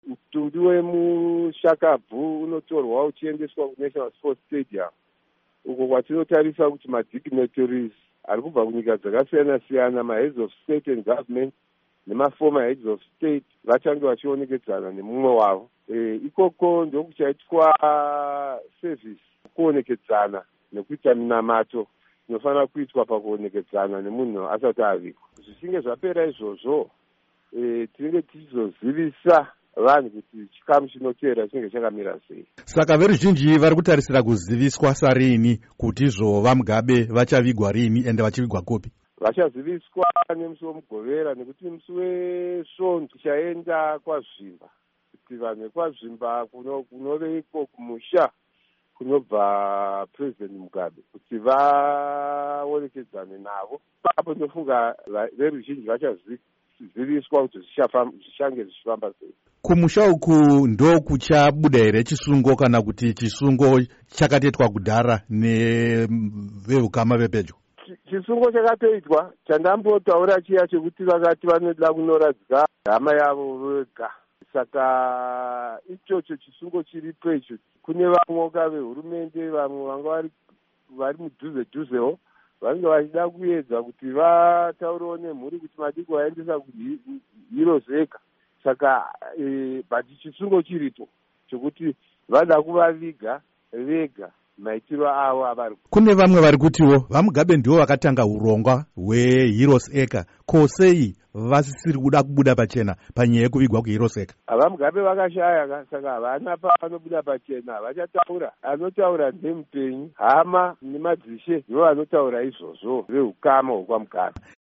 Hurukuro naVaLeo Mugabe